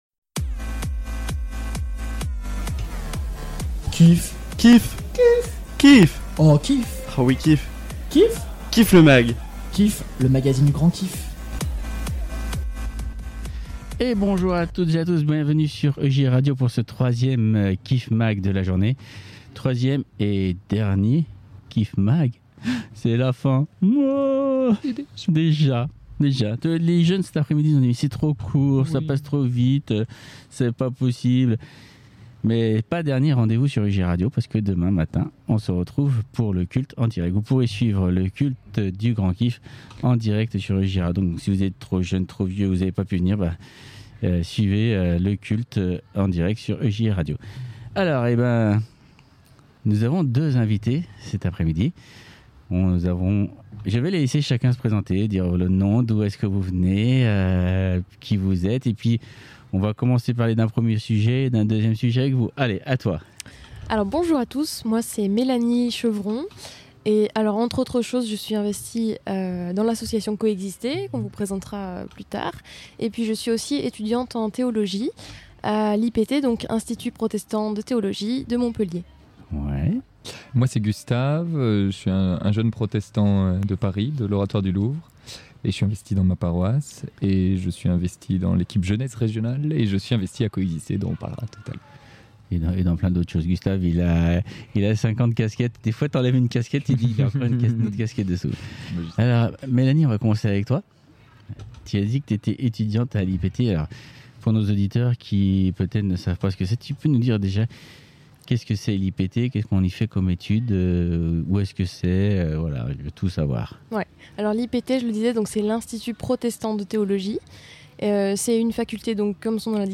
Le 8ème numéro du KIFFMAG en direct de ALBI le 01/08/2021 à 18h00